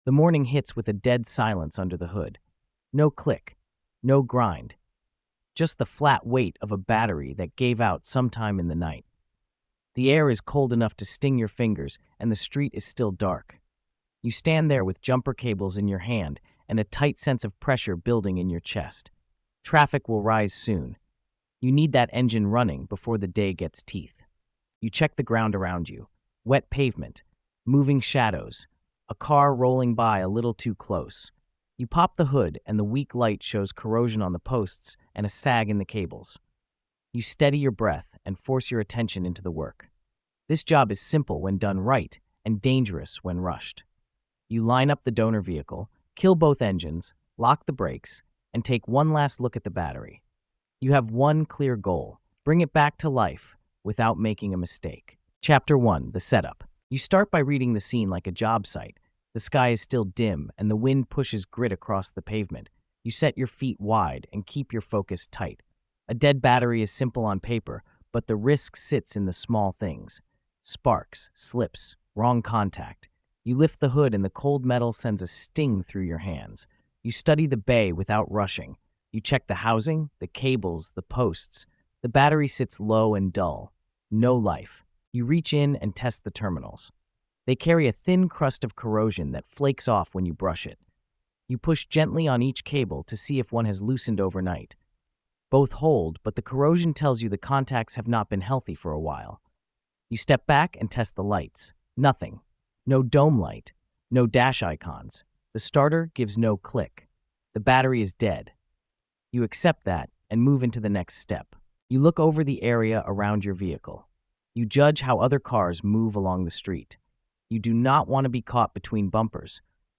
Each chapter pushes you through disciplined checks, safe cable handling, controlled start attempts, and the final revival of the engine. The tone stays gritty and grounded.